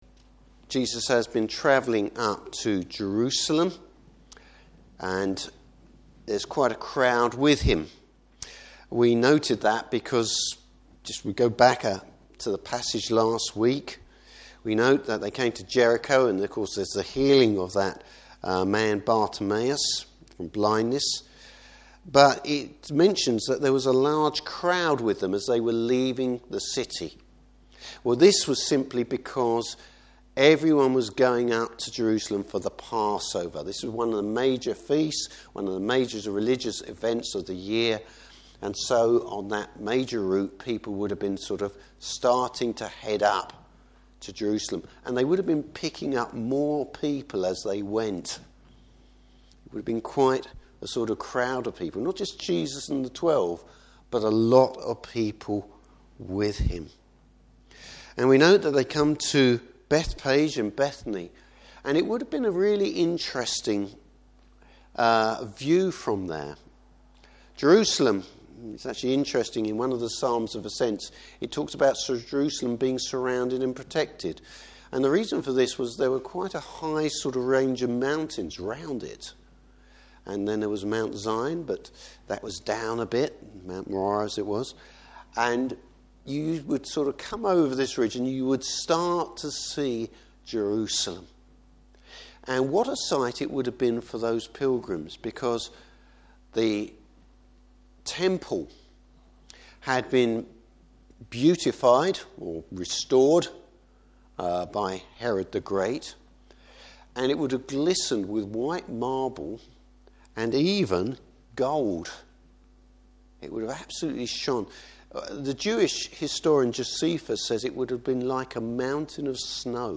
Service Type: Morning Service The style of Jesus’ kingship.